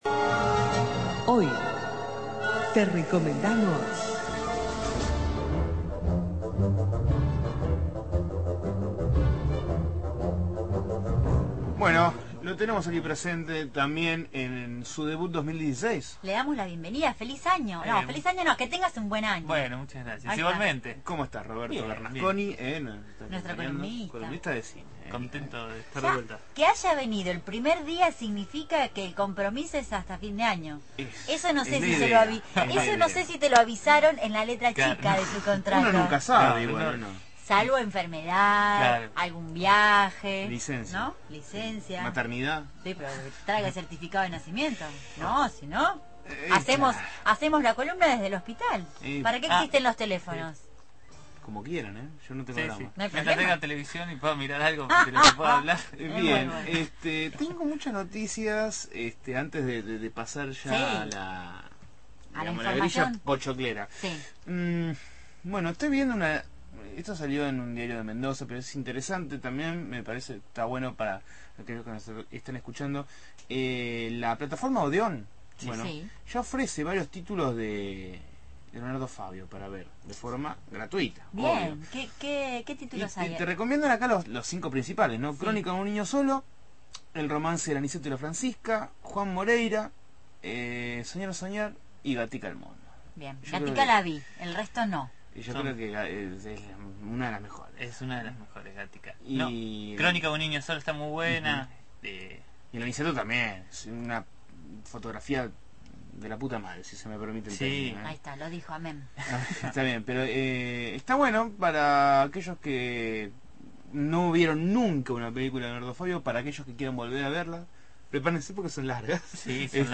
realizó su habitual columna sobre cine en «Dame una señal», en esta oportunidad dedicada a los estrenos de la semana.